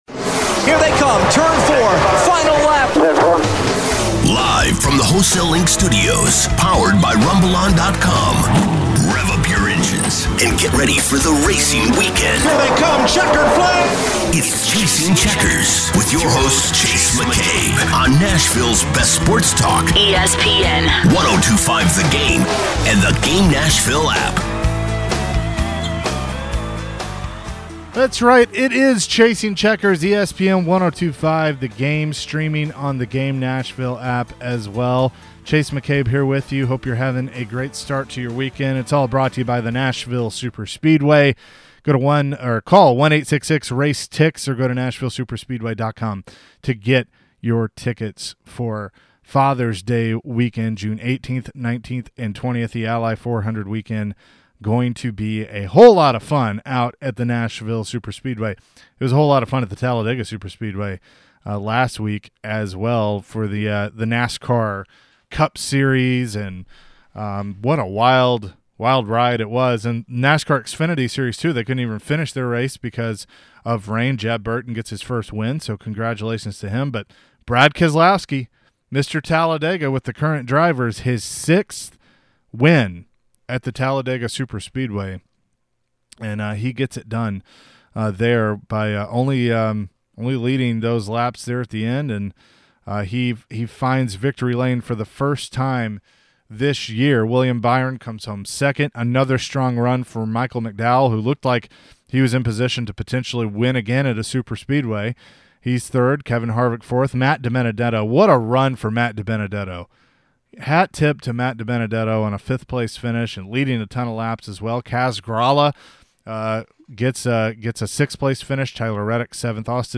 re-visits some great interviews from the year so far